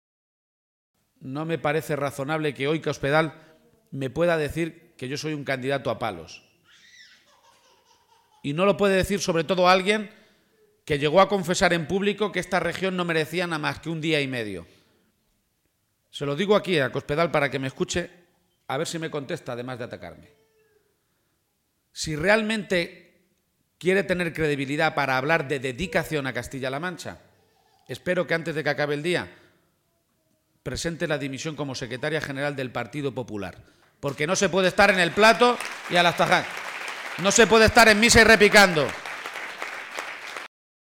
García-Page se pronunciaba de esta manera durante un acto público en la localidad de Alovera.